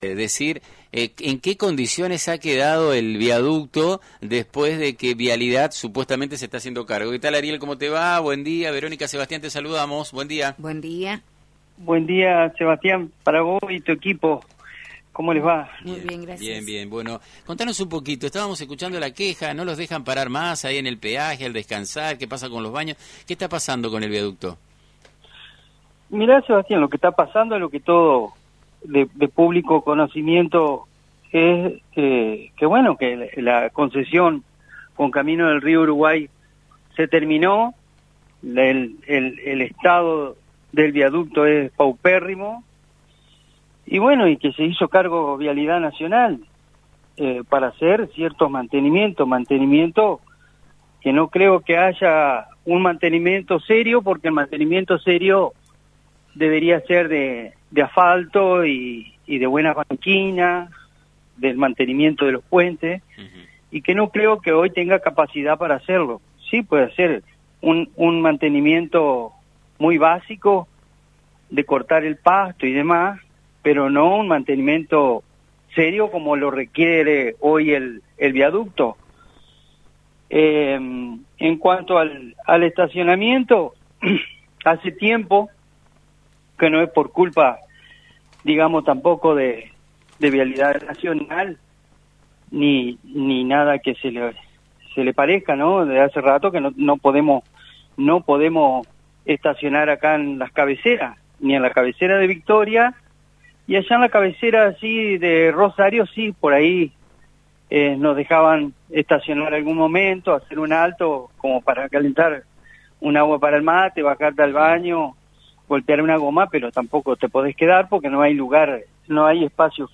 Transportista